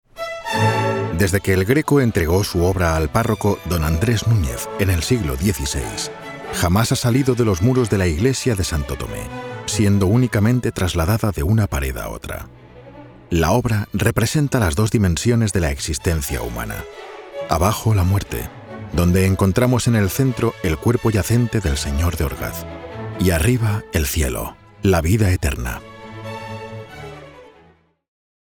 Audioguides
Mikrofon: Neumann TLM-103
Im mittleren Alter
Bass